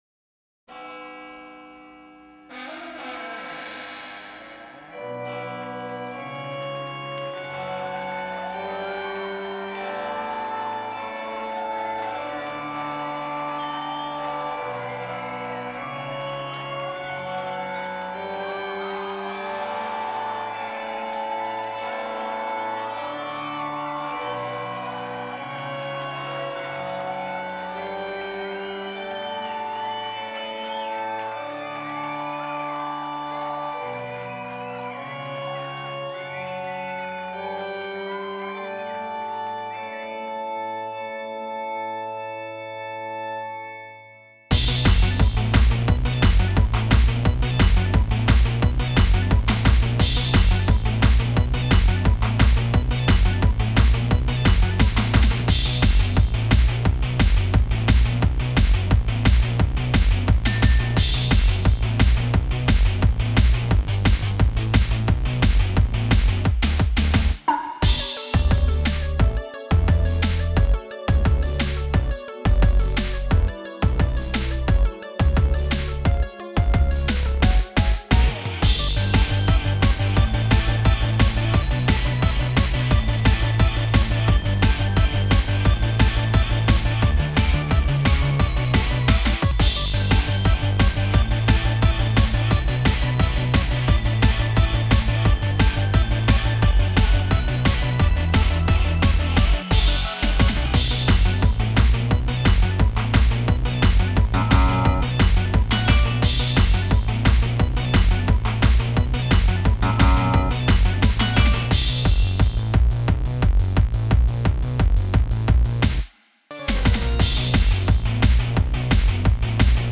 カラオケ音源などもご用意していますので、歌ってみた等は大歓迎です！